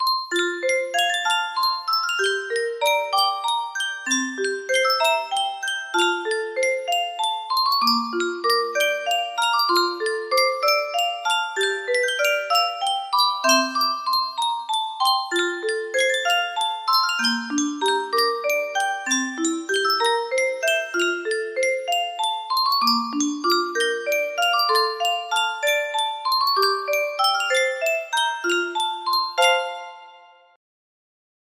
Yunsheng 30 Note Music Box - A Bird in a Gilded Cage music box melody
Full range 60